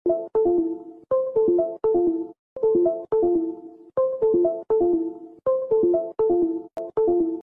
The bluetooth device has connected sound effects free download
The bluetooth device has connected Mp3 Sound Effect